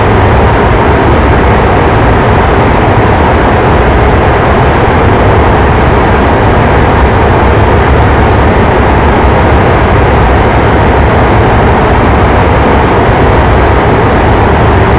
v2500-reverse.wav